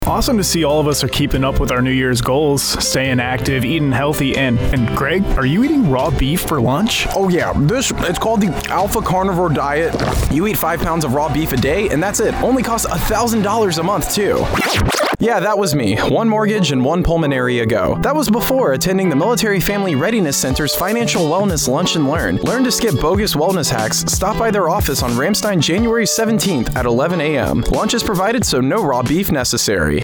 Radio Spot - Financial Wellness Lunch and Learn
This is a 30-second radio advertisement publicizing the Ramstein Military and Family Readiness Center's Financial Wellness Lunch and Learn, happening Jan. 17, 2023, on Ramstein Air Base, Germany.